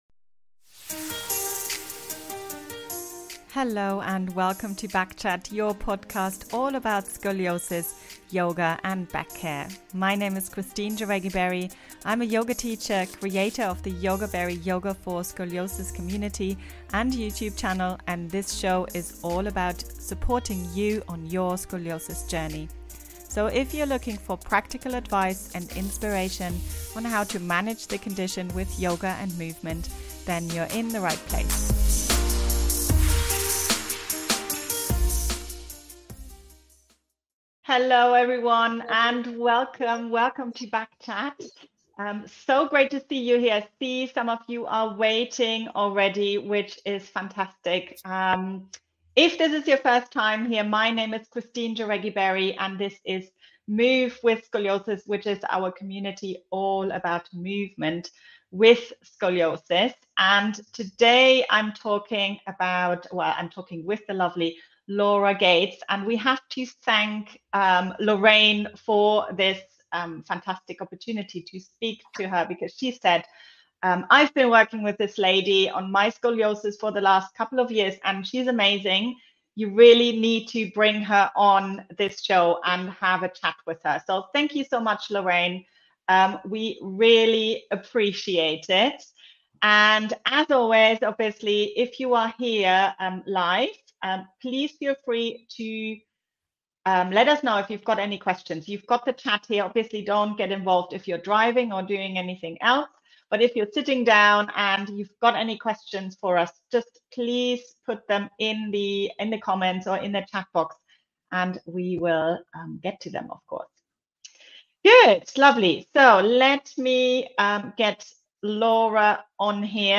78. Resetting Scoliosis Patterns - Interview